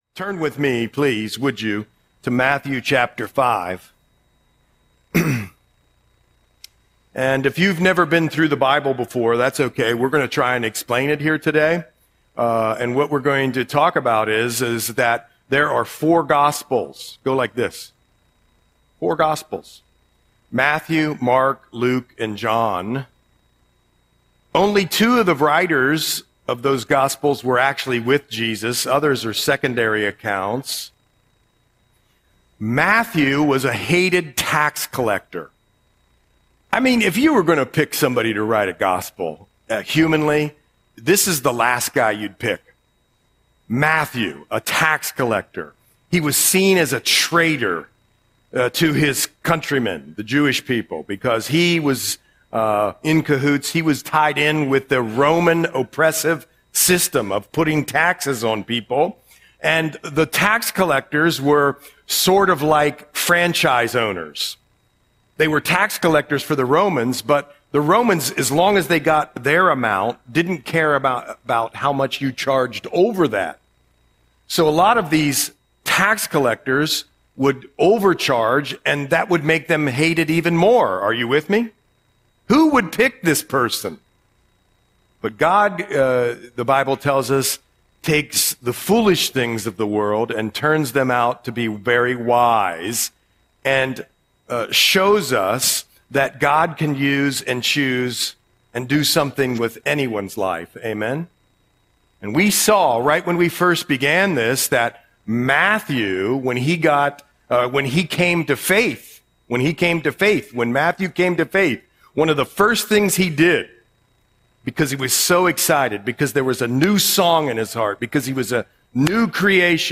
Audio Sermon - November 9, 2025